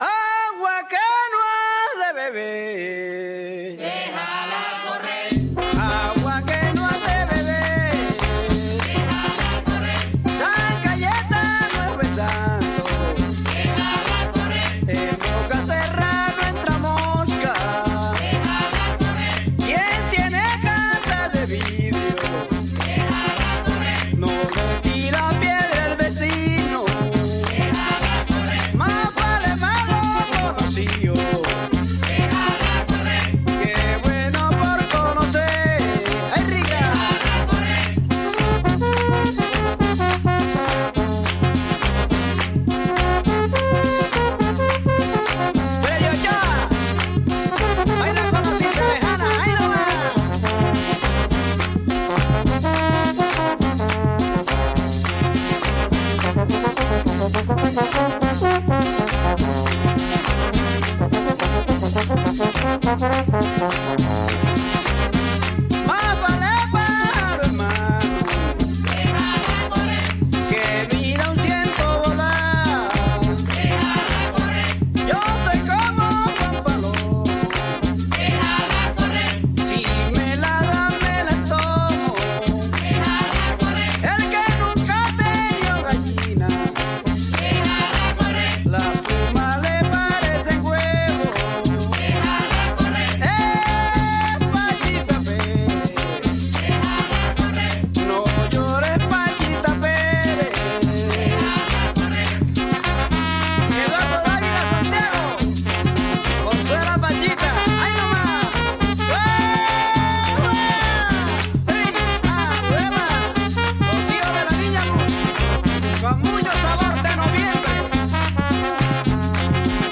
cumbia